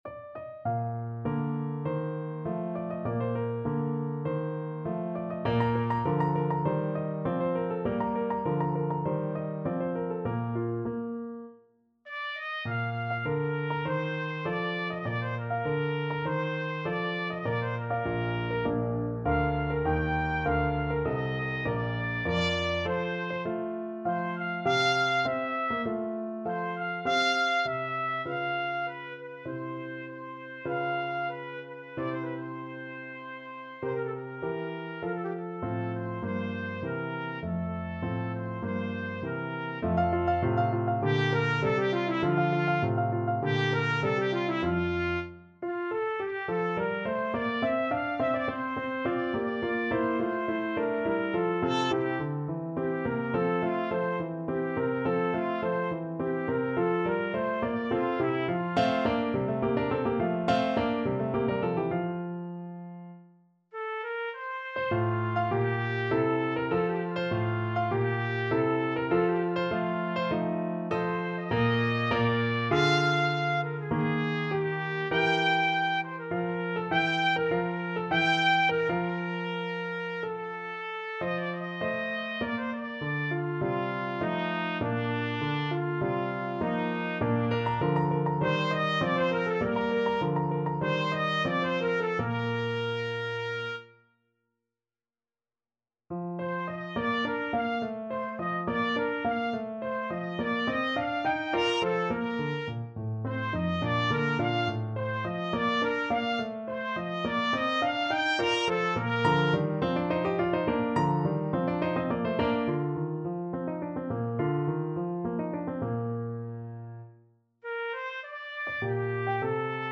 Classical Pergolesi, Giovanni Battista A Serpina Penserete from La Serva padrona Trumpet version
Trumpet
Bb major (Sounding Pitch) C major (Trumpet in Bb) (View more Bb major Music for Trumpet )
2/4 (View more 2/4 Music)
~ = 50 Larghetto
Classical (View more Classical Trumpet Music)